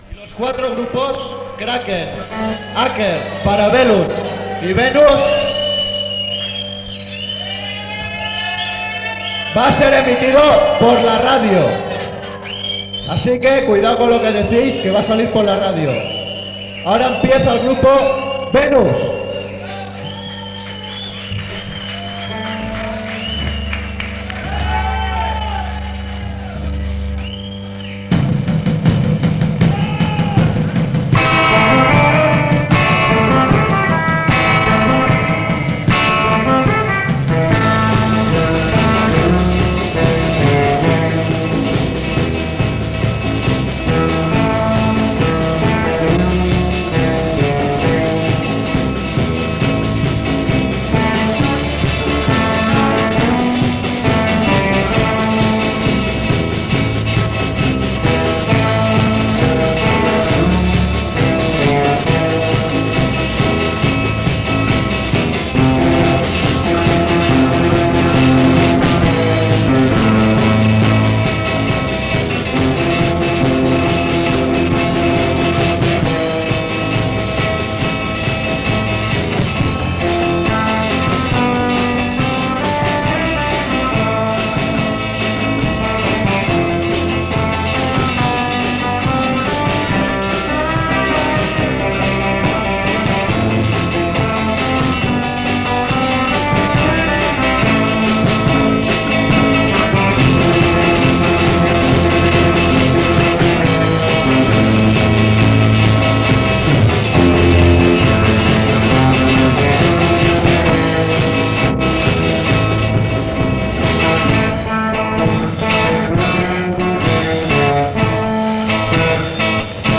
Transmissió de la Festa de Ràdio Farigola a Nou Barris on actúa el grup Venus